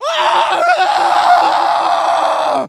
scream_short_4.ogg